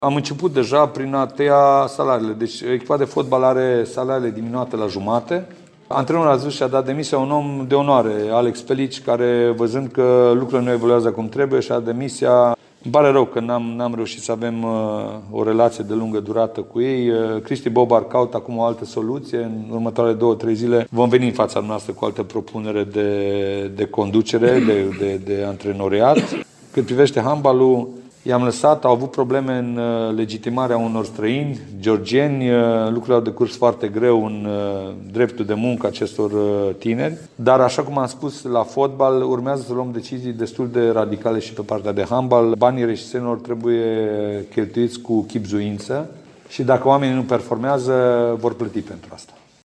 Popa a mai spus că banii reşiţenilor trebuie cheltuiţi cu chibzuinţă: